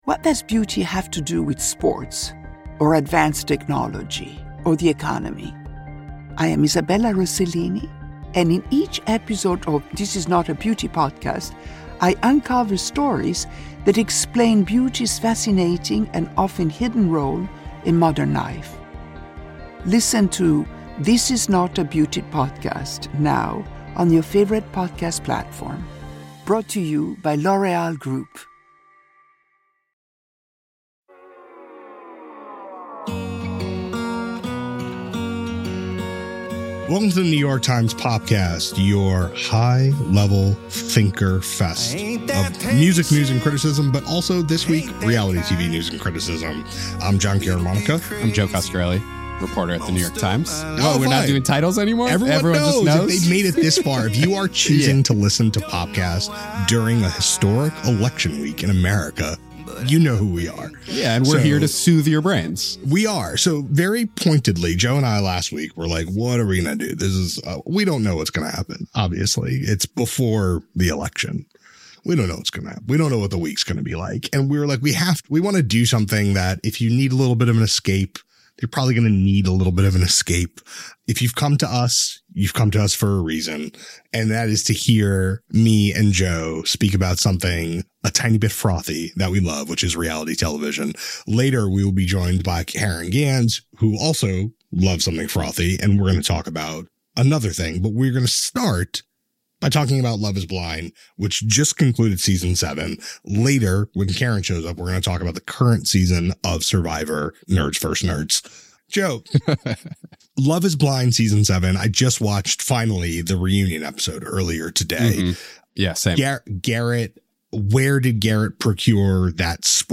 A palate-cleanse conversation about the state of legacy reality franchises, and what might come next for them.